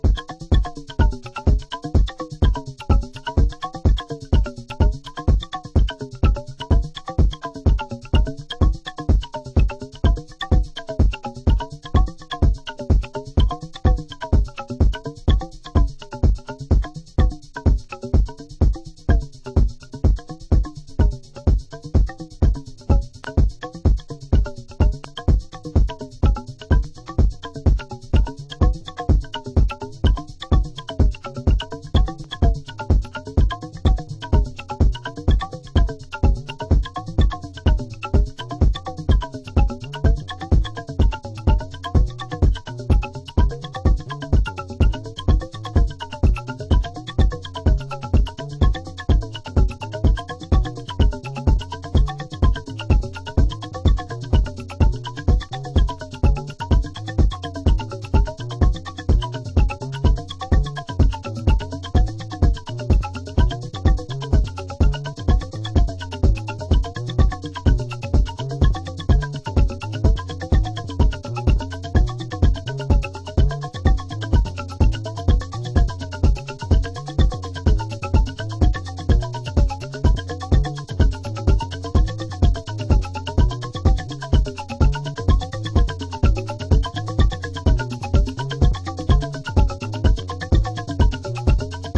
Raw and deep as we like it.
House